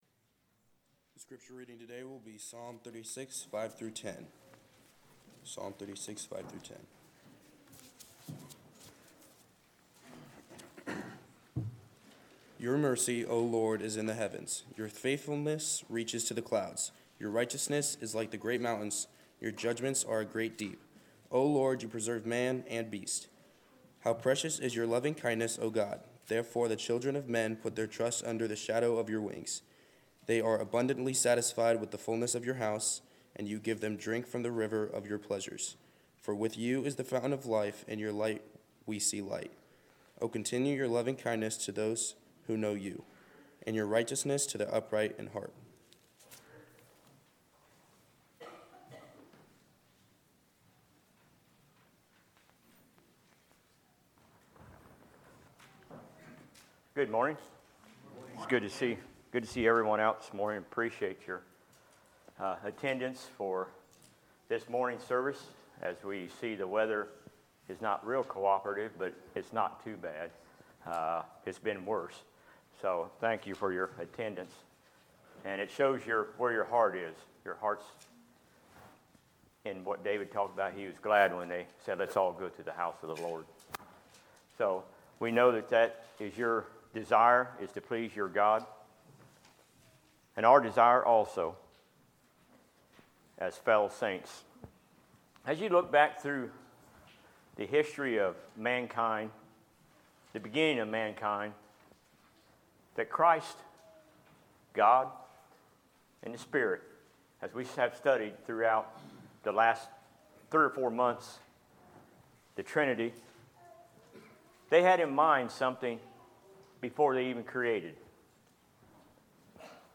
Sermons, January 26, 2020